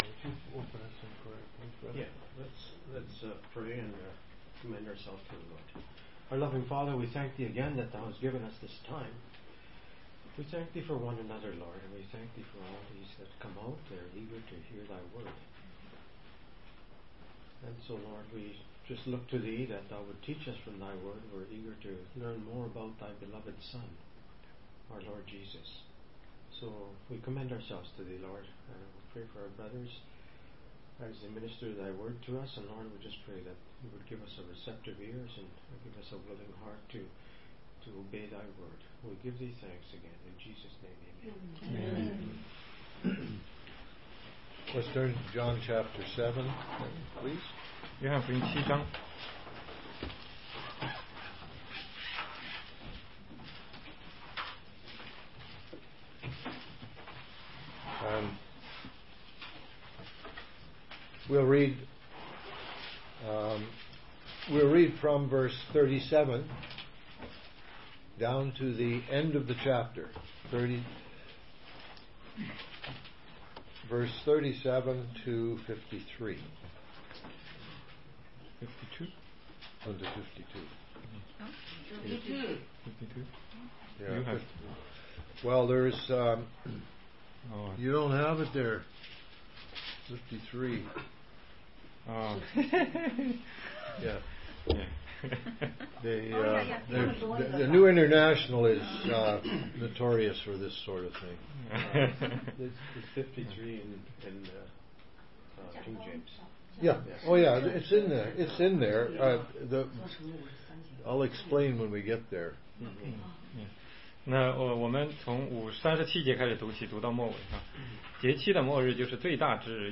16街讲道录音 - 约翰福音7章37-39节